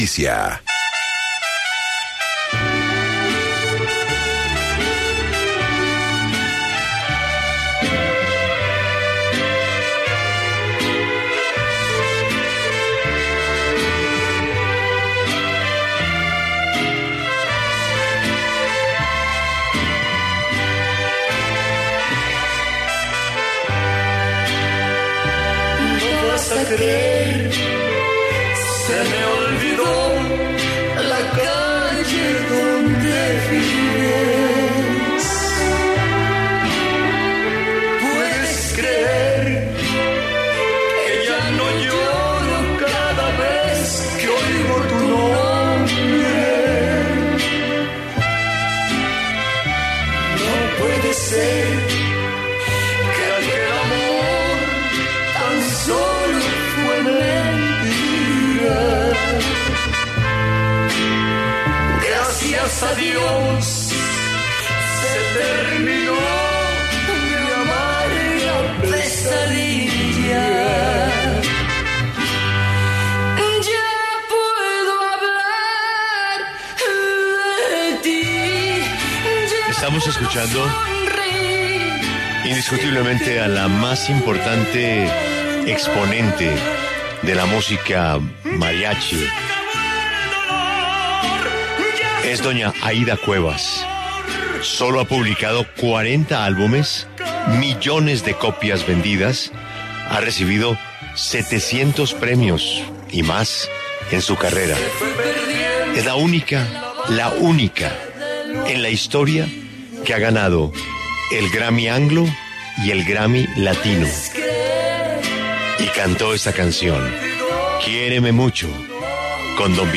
En el encabezado escuche la entrevista completa con la cantante Aida Cuevas.